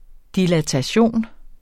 dilatation substantiv, fælleskøn Bøjning -en, -er, -erne Udtale [ dilataˈɕoˀn ] Oprindelse fra latin dilatare 'udvide' af dis- og latus 'bred, vid' Betydninger 1.